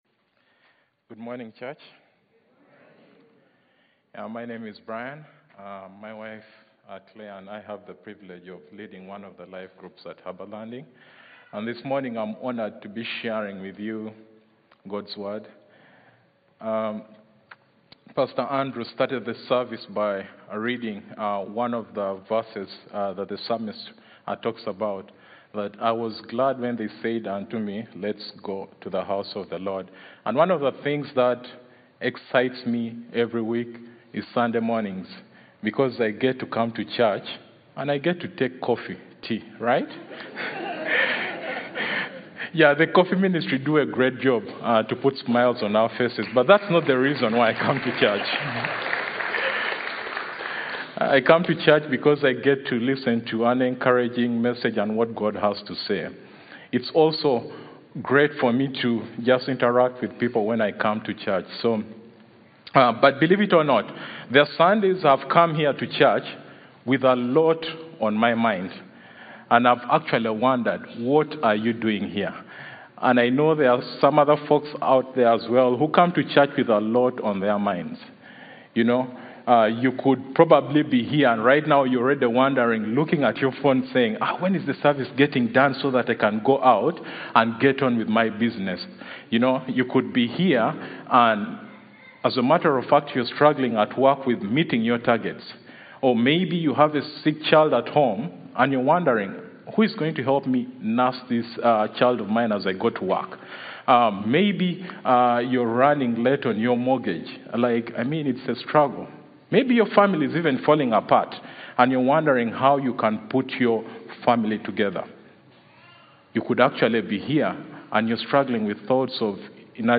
Stand Alone Message SAFE IN THE FATHER’S HAND June 30, 2024 Safe in the Father's Hand Life is full of challenges and struggles.